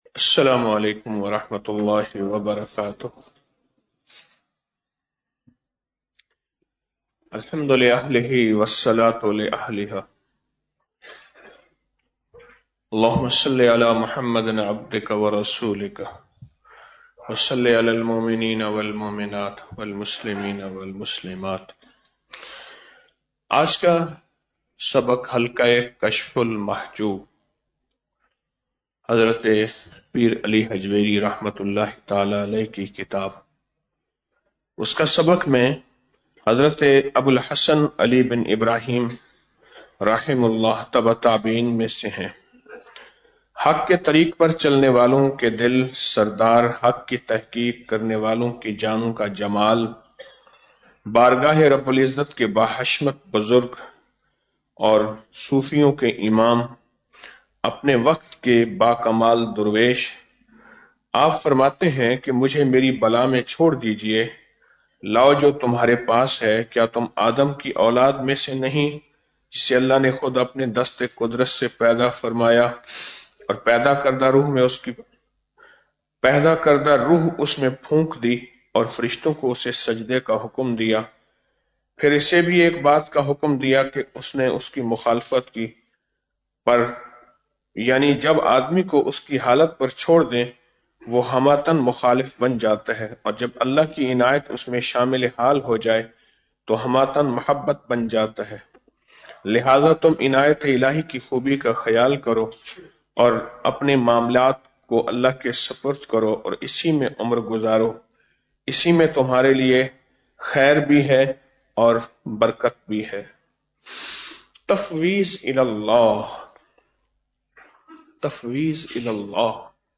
Audio Speech - 06 Ramadan After Salat Ul Fajar - 07 March 2025